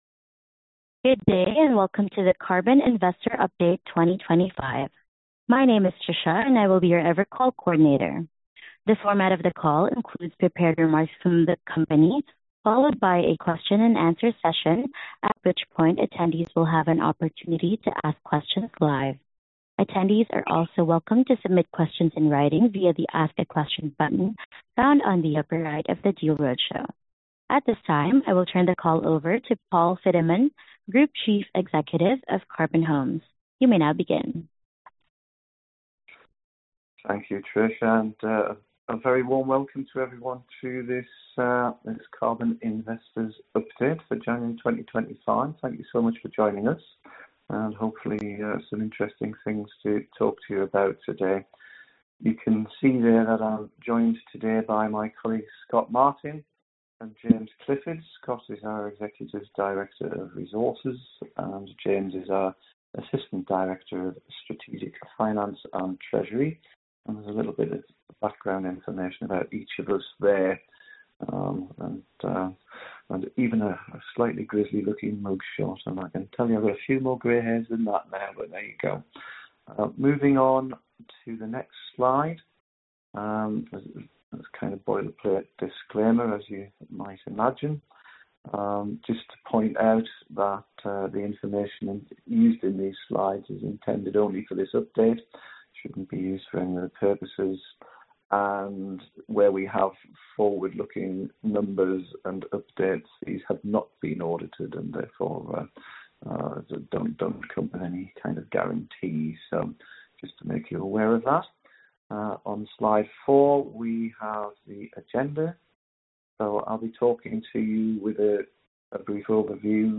Investor Presentation - 16 January 2025 (Audio)